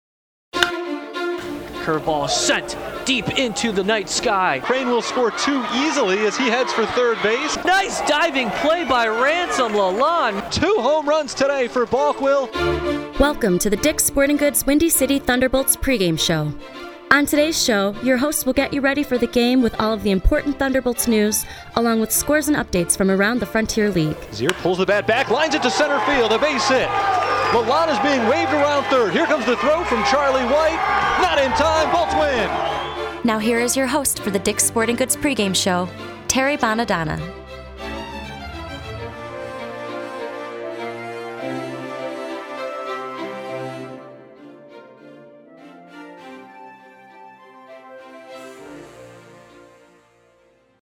This is the intro that I produced to open each broadcast: